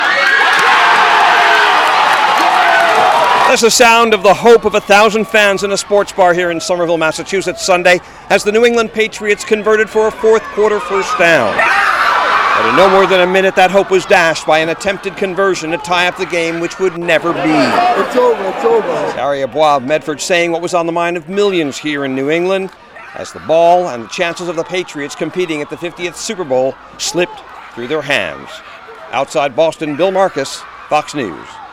Nearly a thousand New England Patriots fans jammed into a sports bar in Somerville, Massachusetts to cheer on their team.